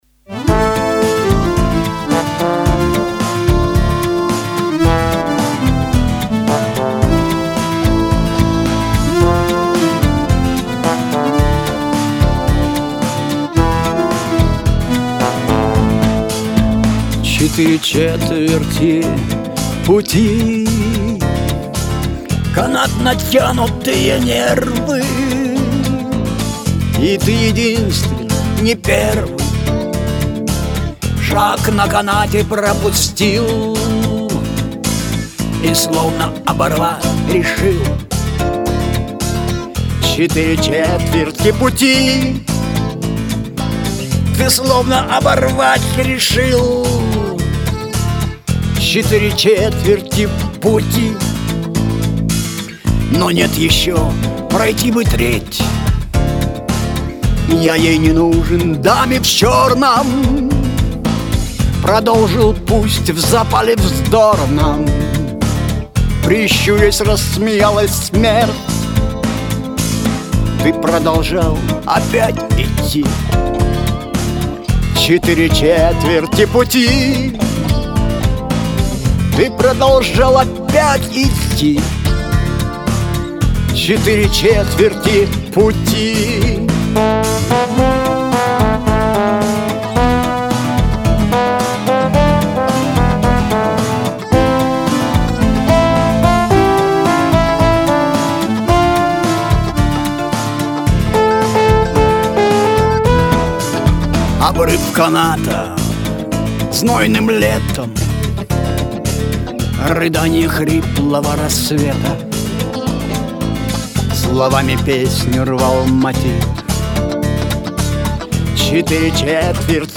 Красивое исполнение... give_rose friends